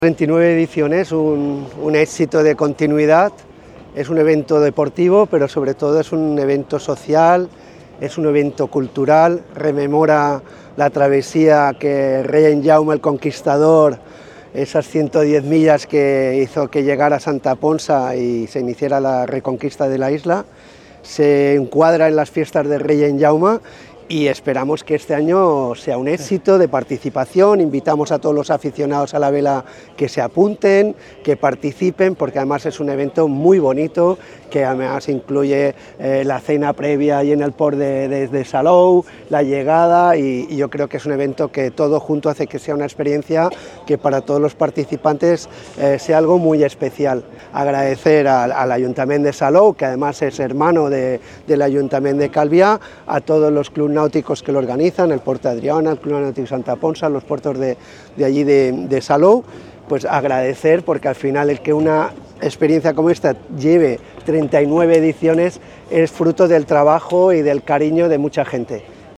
declaraciones-alcalde-juan-antonio-amengual.mp3